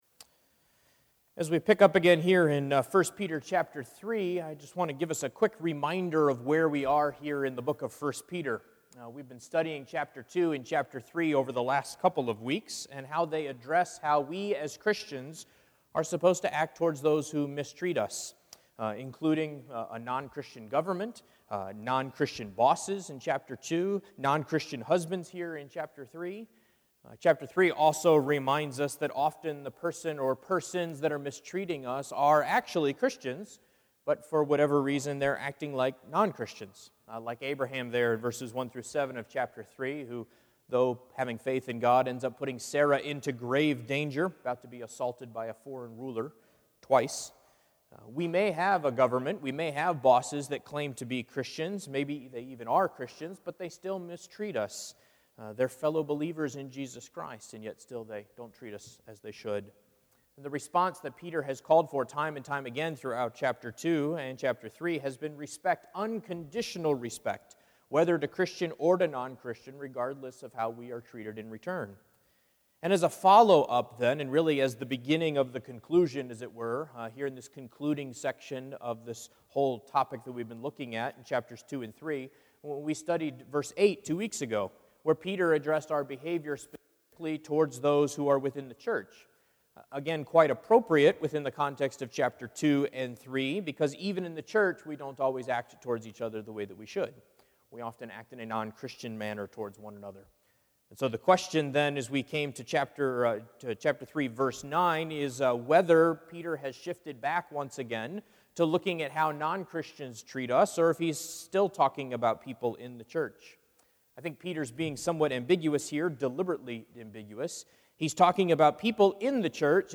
1 Peter 3:9-17 Service Type: Sunday Morning %todo_render% « Golden Chain of Salvation